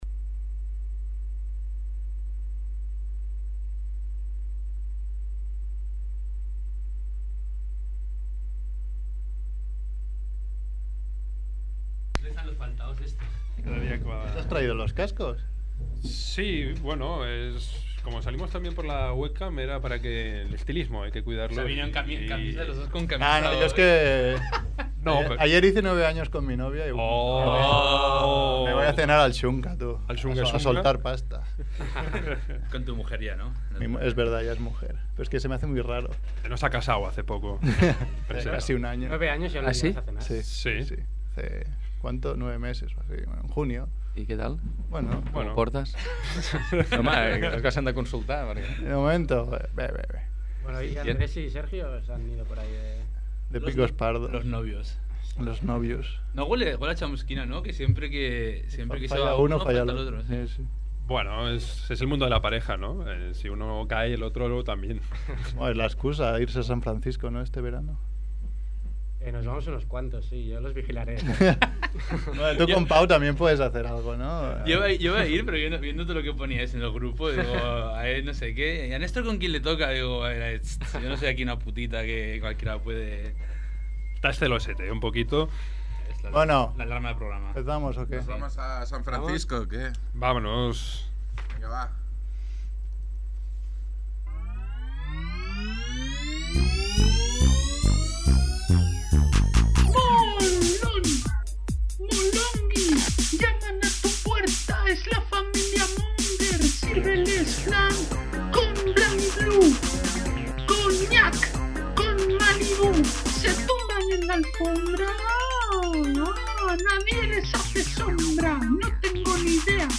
Tercera semana consecutiva con un integrante de Rac1 (la radio líder de Catalunya) en el estudio.